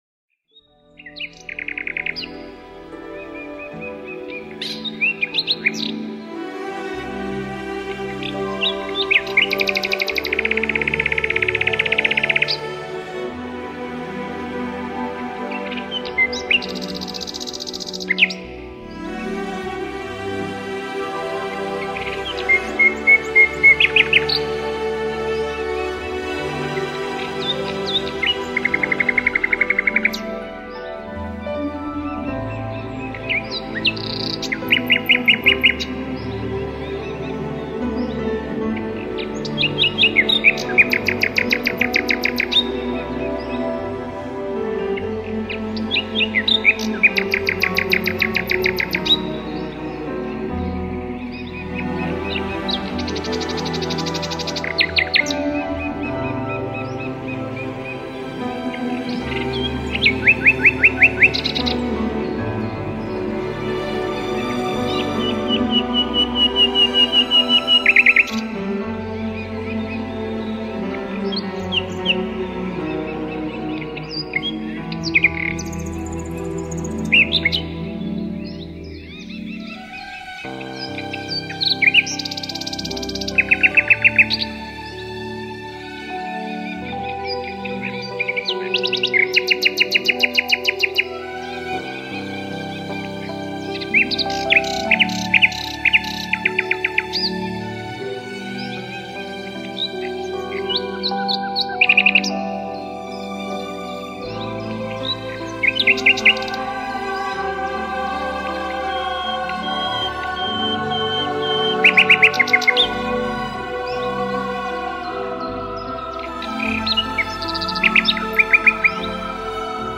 Всем хороших выходных!{-29-} Соловьиное соло.